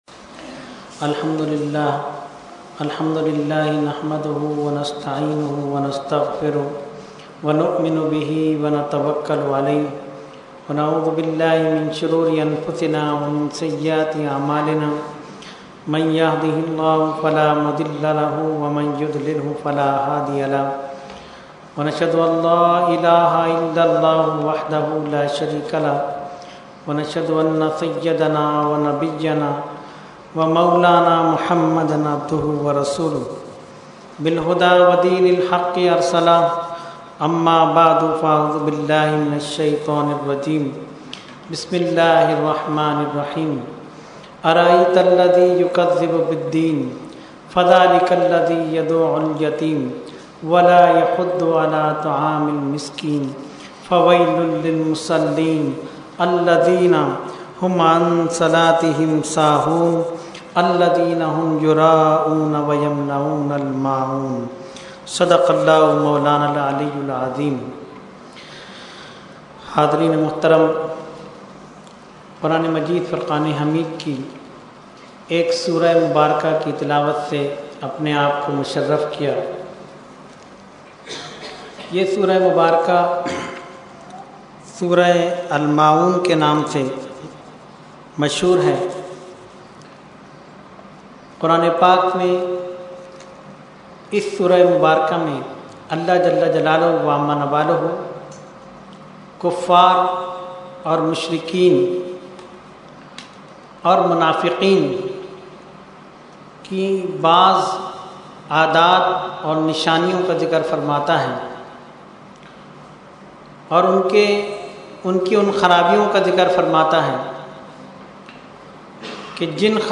Weekly Tarbiyati Nashist held on 10/3/2013 at Dargah Alia Ashrafia Ashrafabad Firdous Colony Karachi.
Category : Speech | Language : UrduEvent : Weekly Tarbiyati Nashist